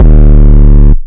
REDD 808 (22).wav